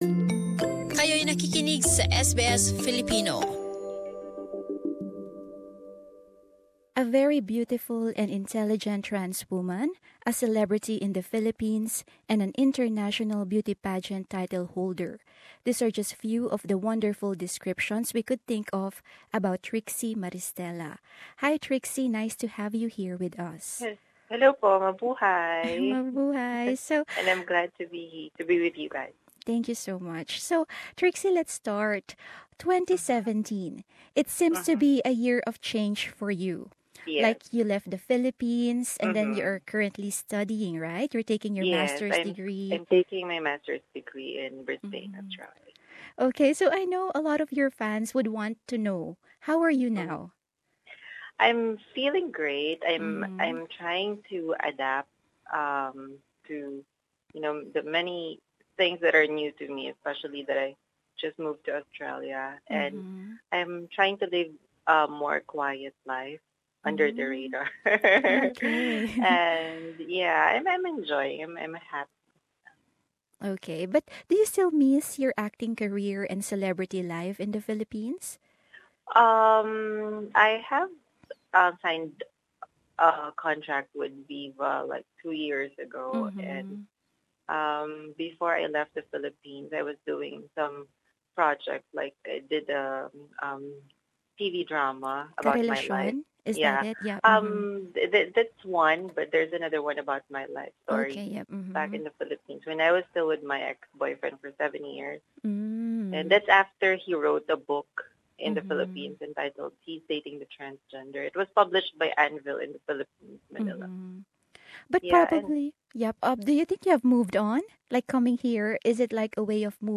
Ang panayam